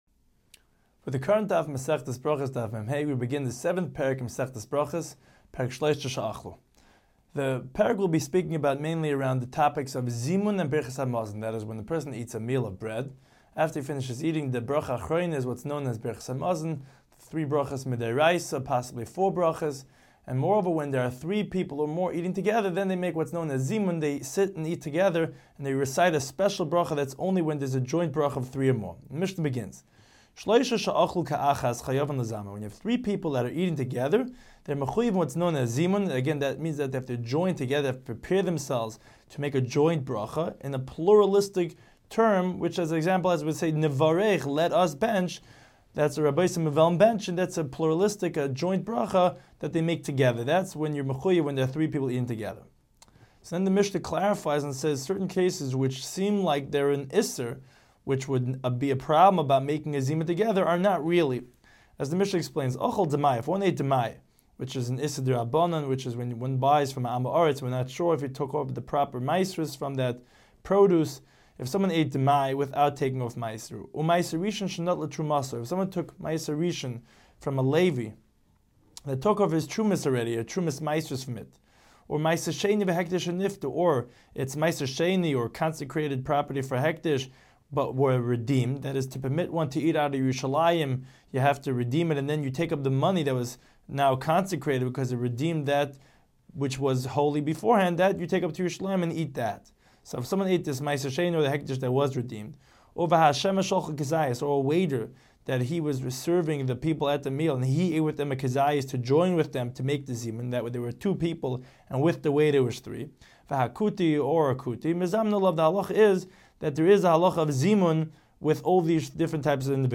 Daf Hachaim Shiur for Berachos 45